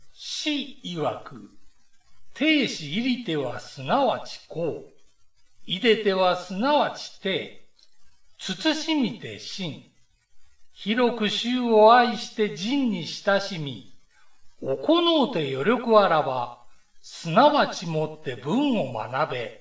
下の ＜朗読音声＞ をクリック又はタップすると、朗読音声が流れます。